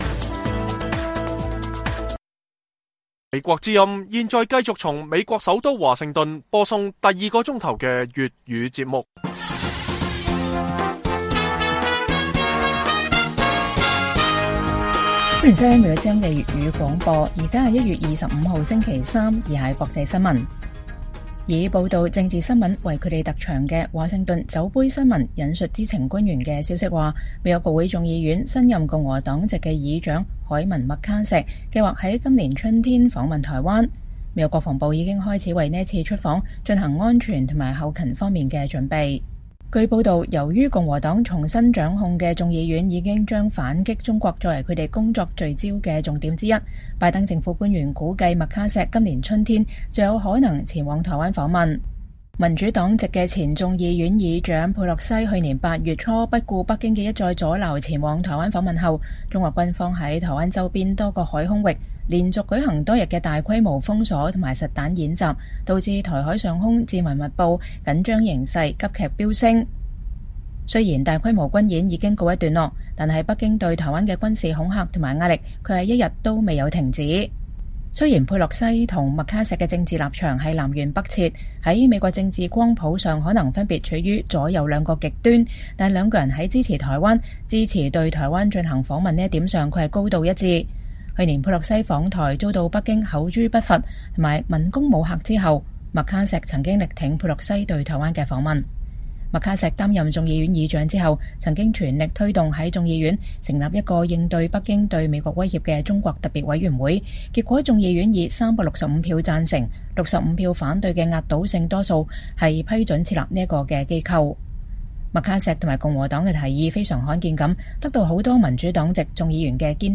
北京時間每晚10－11點 (1400-1500 UTC)粵語廣播節目。內容包括國際新聞、時事經緯和社論。